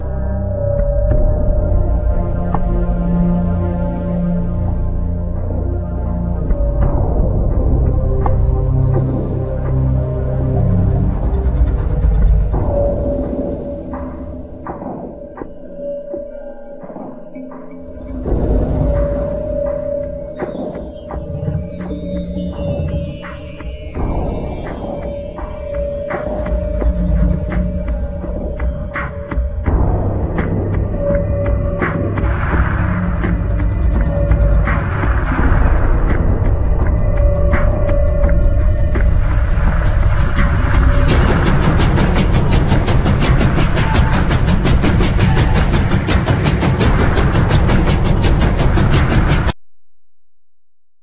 Electronics,Percussion,Voice